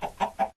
chicken1.ogg